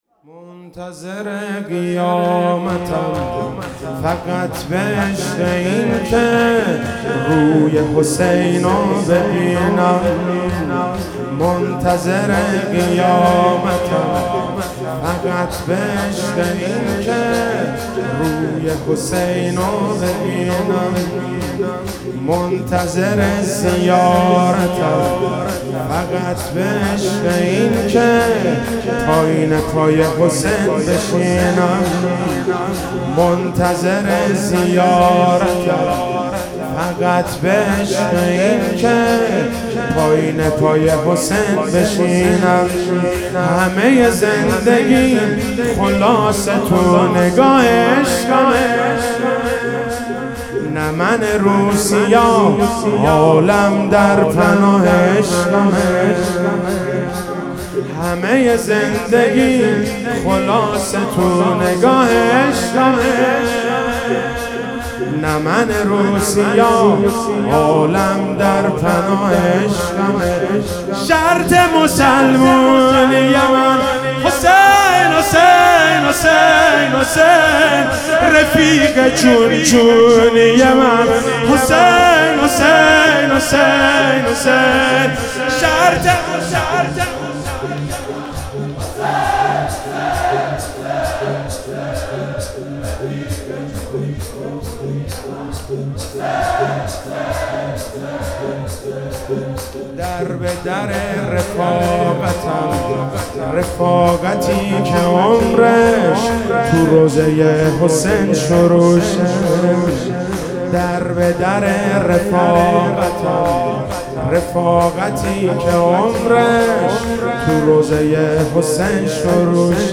فاطمیه99